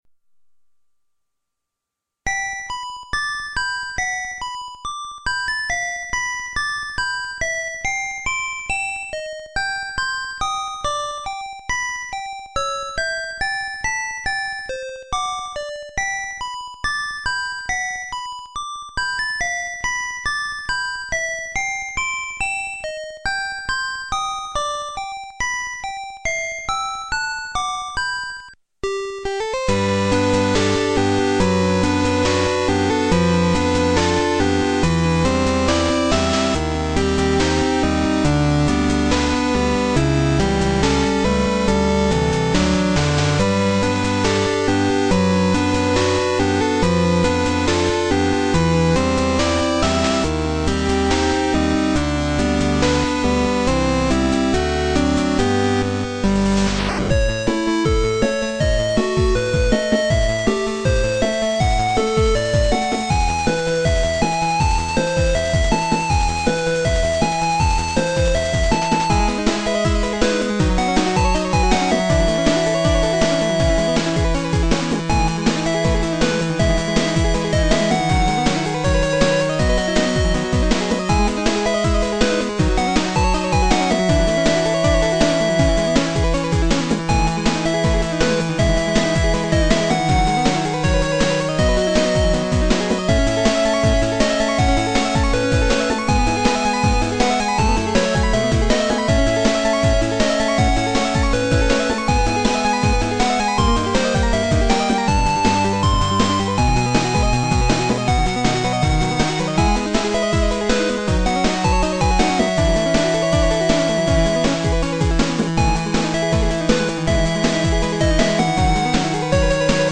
BPM140-160
Audio QualityCut From Video